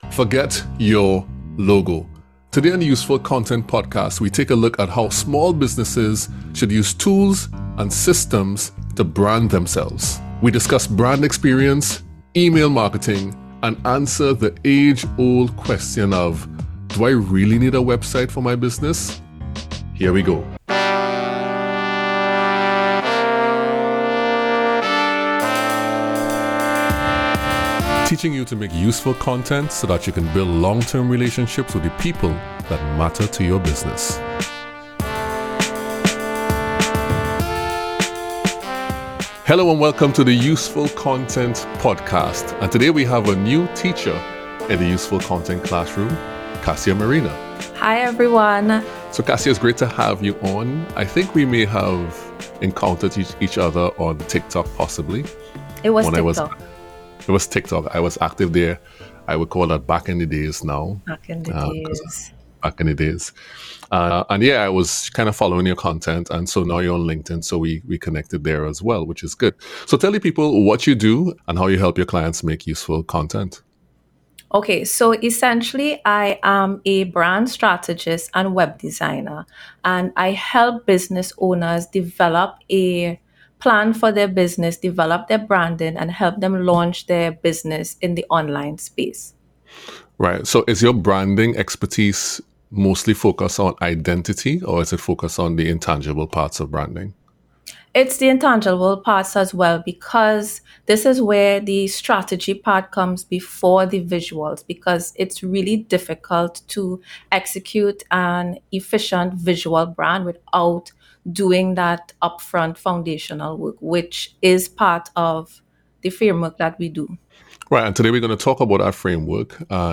The discussion unfolds around branding